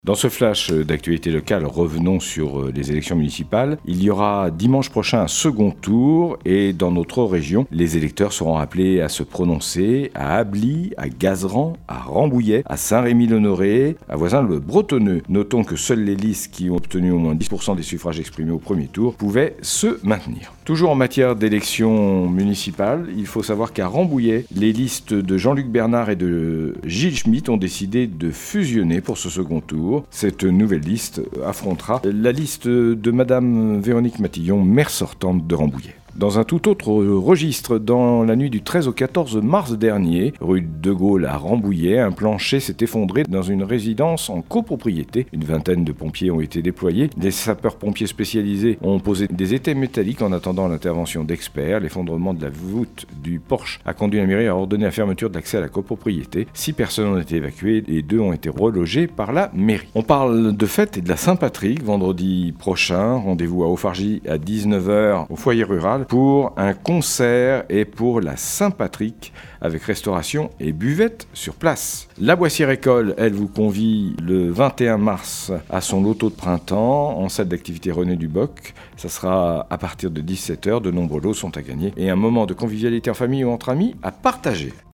18.03-FLASHLOCAL-MATIN.mp3